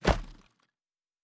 Stone 02.wav